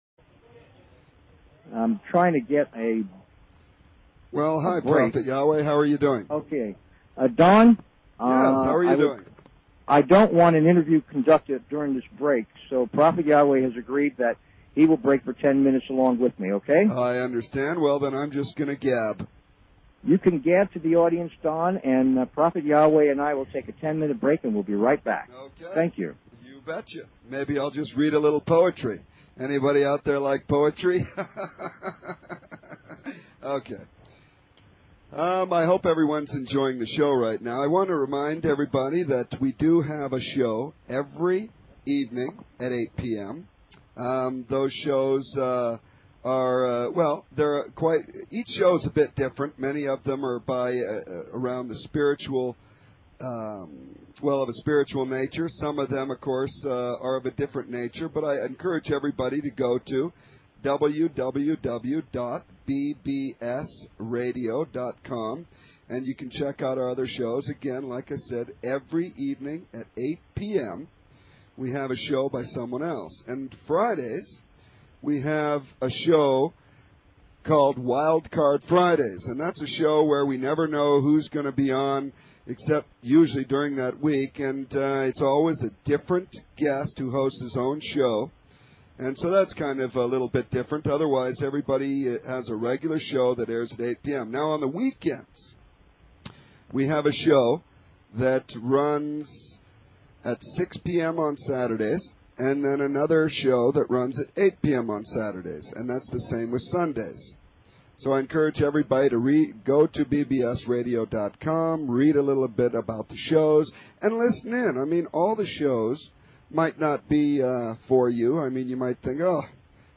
Talk Show Episode, Audio Podcast, What_A_World and Courtesy of BBS Radio on , show guests , about , categorized as